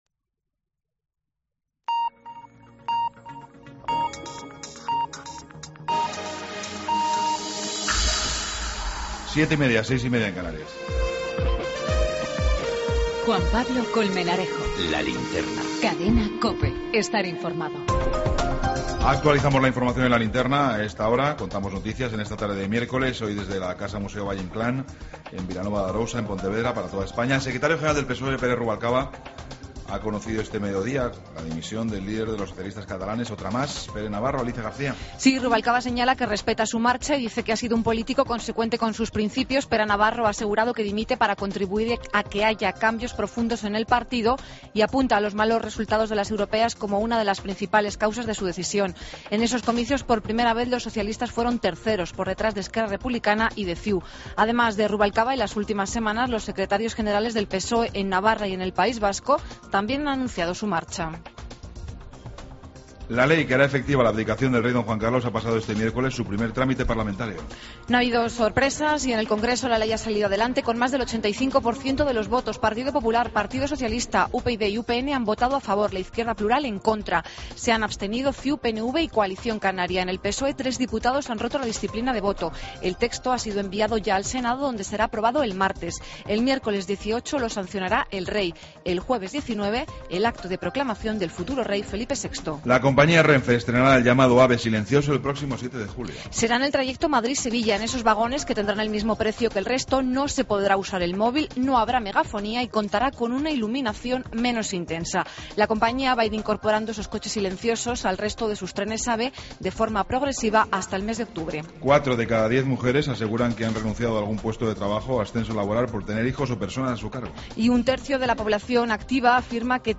Ronda de corresponsales. Entrevista